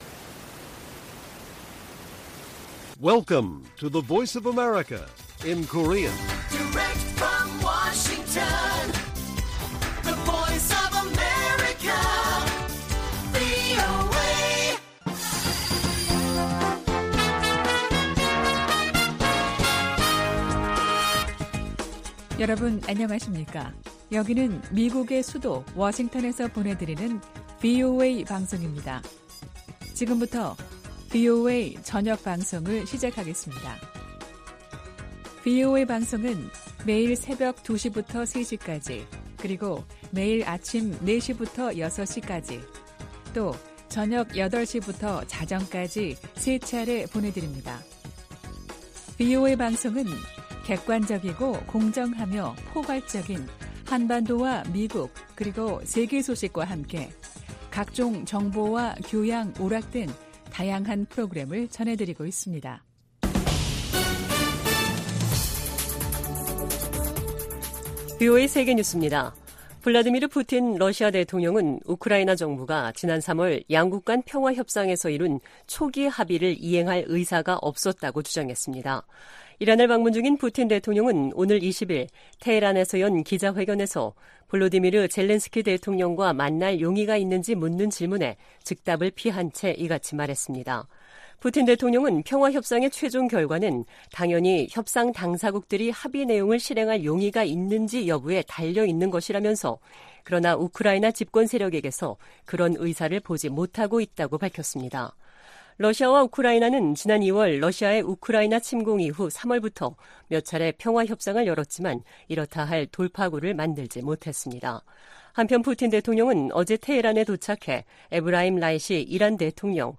VOA 한국어 간판 뉴스 프로그램 '뉴스 투데이', 2022년 7월 20일 1부 방송입니다. 미 국무부 ‘2022 인신매매 실태 보고서’에서 북한이 20년 연속 최악의 인신매매 국가로 지목됐습니다.